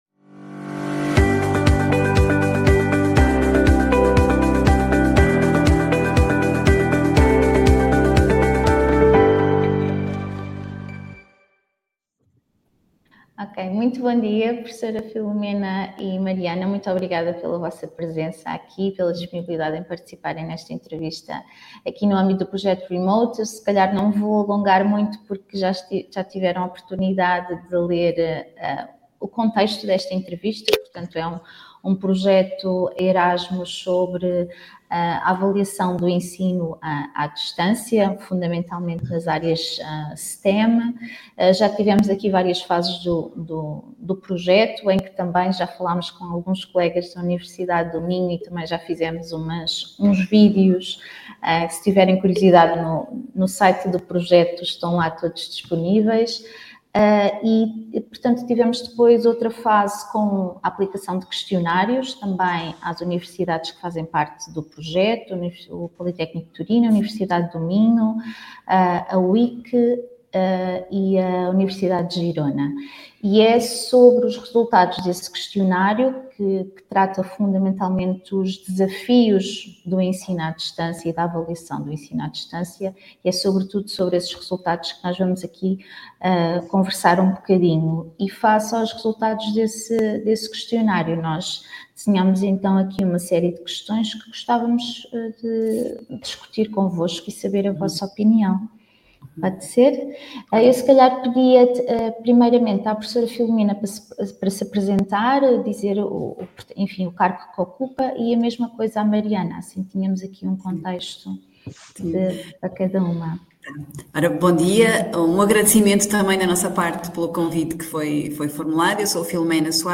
In-Depth Interviews
Entrevista-conversa